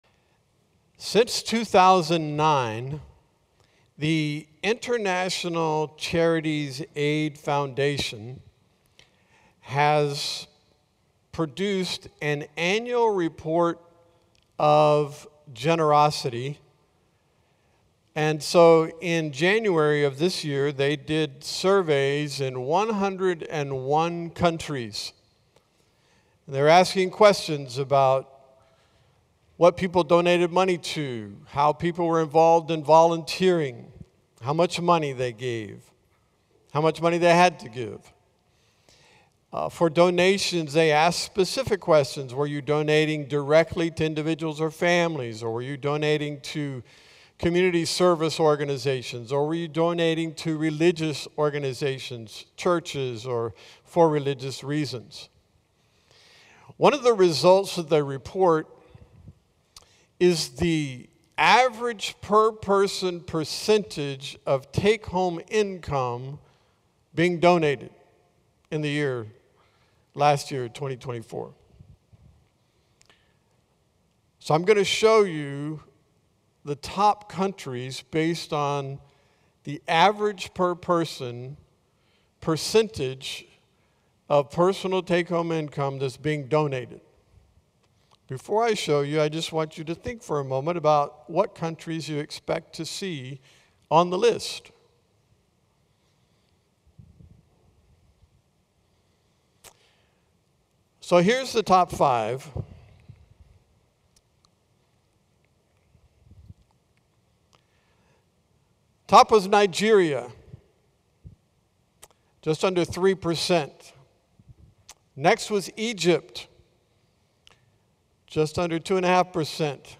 A message from the series "When We Give."